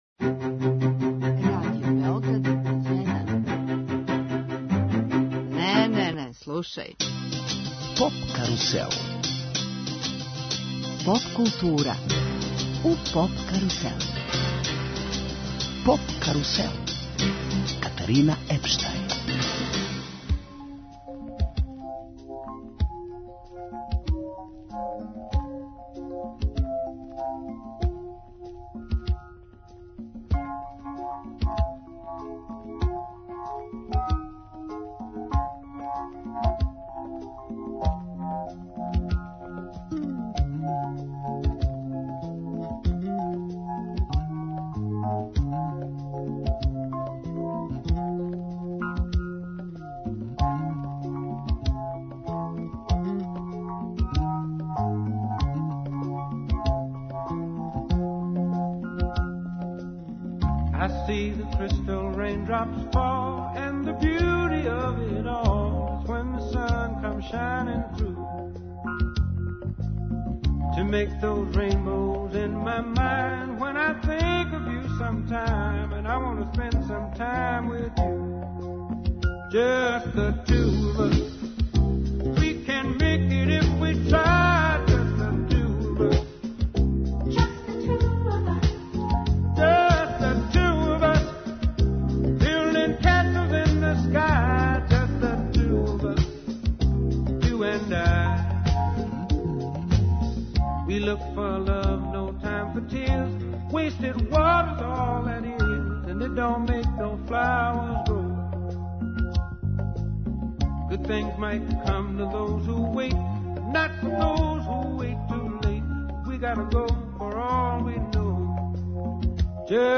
Емисија из домена популарне културе.